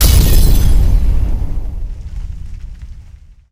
nuke_explode.3.ogg